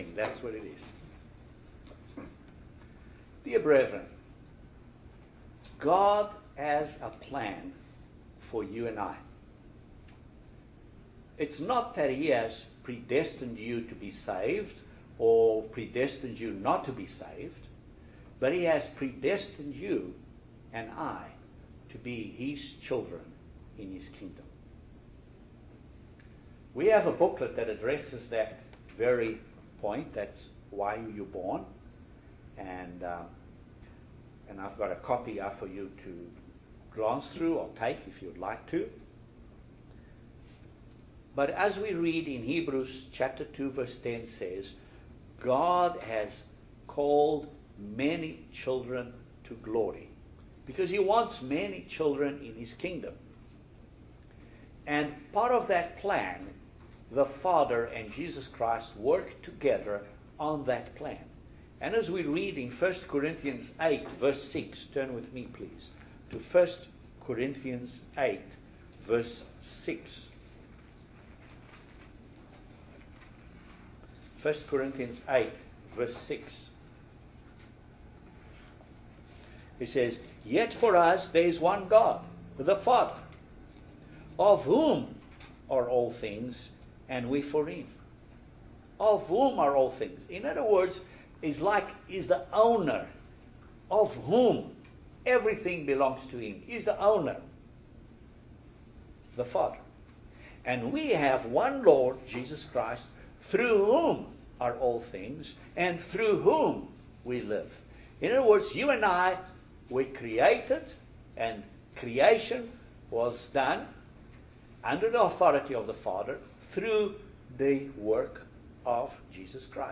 Excellent video sermon on Baptism into the family of God.